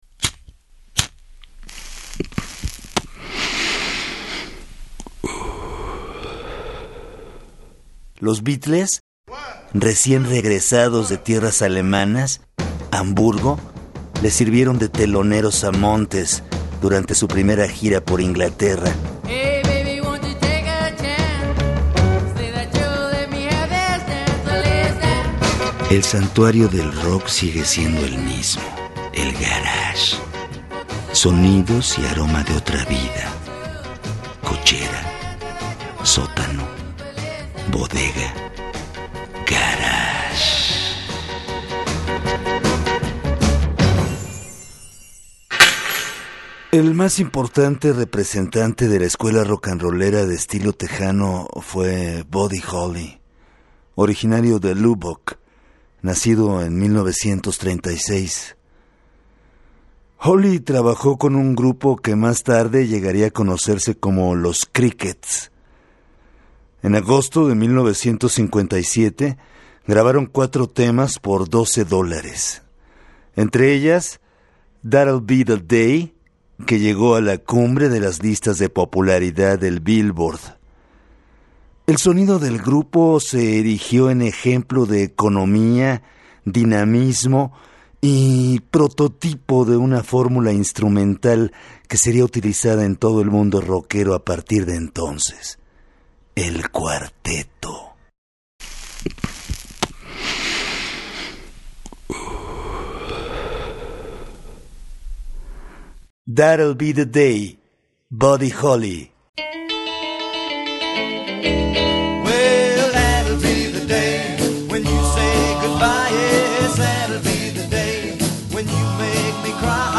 rock chicano